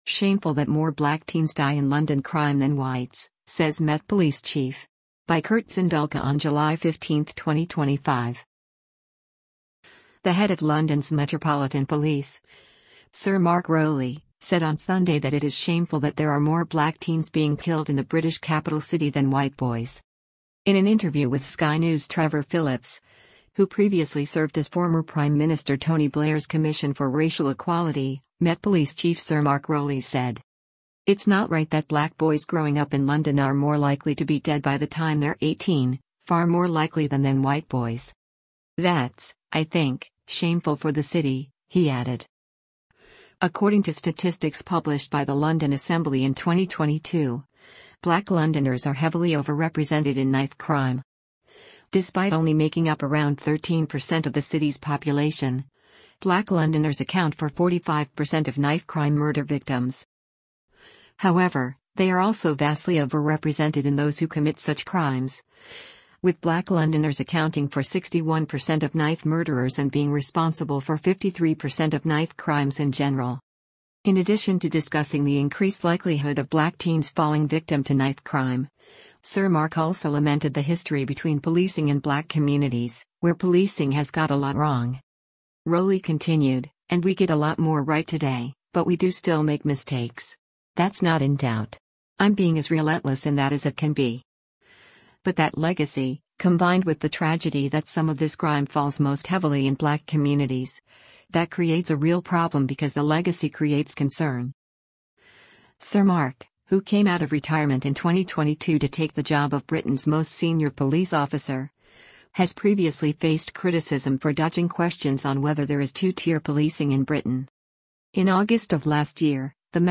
In an interview with Sky News’ Trevor Phillips, who previously served as former Prime Minister Tony Blair’s Commission for Racial Equality, Met Police Chief Sir Mark Rowley said: